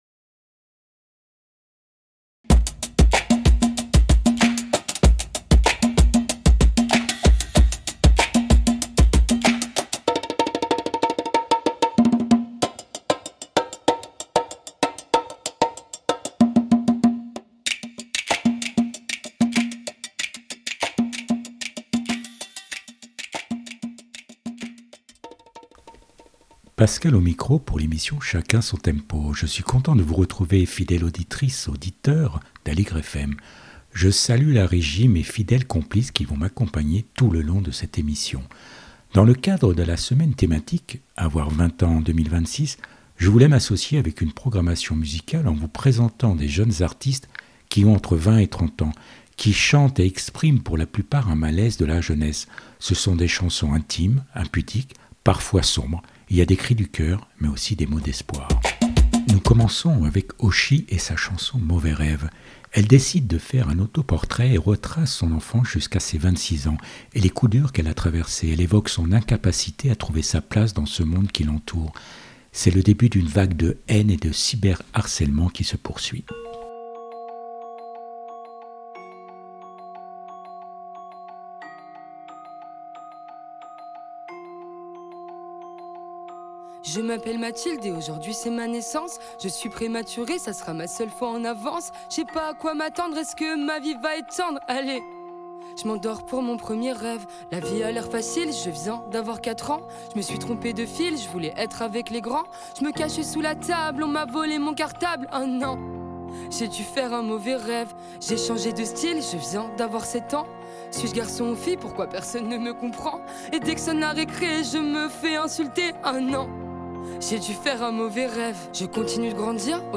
Une émission hors série dans le cadre de la semaine thématique « Avoir 20 ans en 2026 » sur Aligre FM
Des portraits de jeunes artistes qui ont entre 20 et 30 ans, qui chantent et expriment pour la plupart un malaise de la jeunesse, ce sont des chansons intimes, parfois sombres, un regard sur un monde qui va mal, il y a des cris du coeur, mais aussi des mots d’espoir.